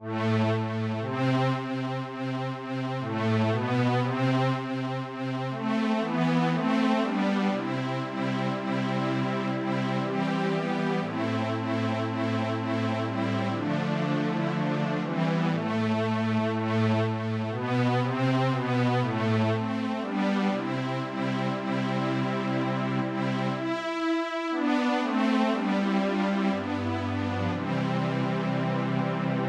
tropar_panteleimonu.mp3